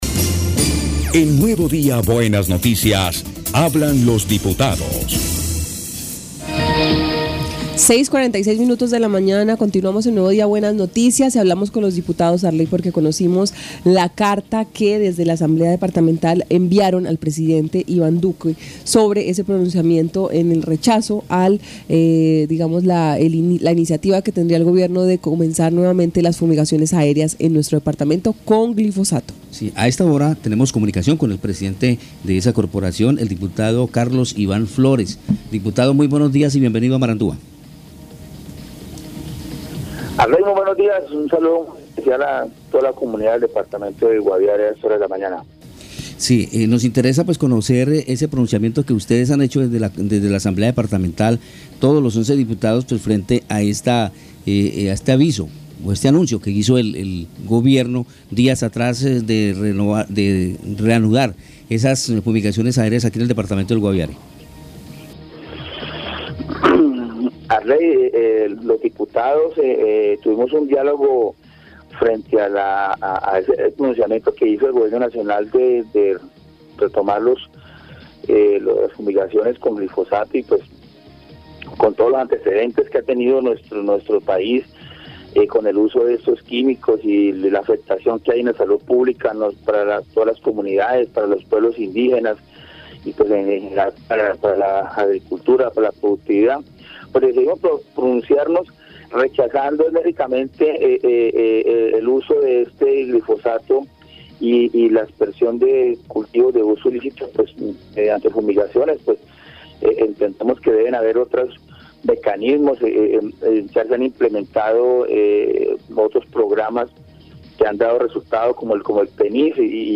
Escuche a Iván Florez, diputado del Guaviare.